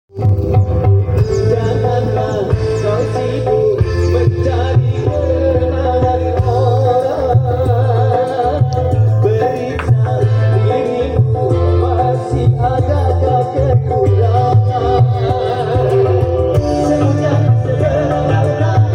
cek sound MJB_Audio 4 sub jarak 100m